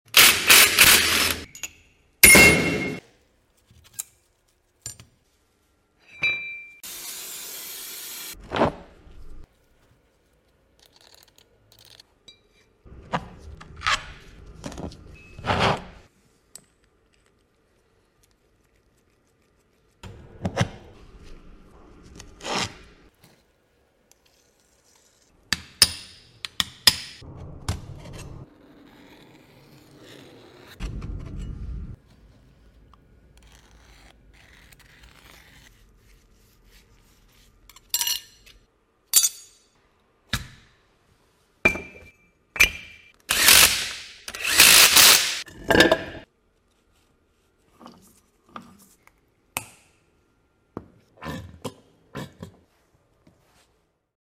ASMR of E46 M3 LSD sound effects free download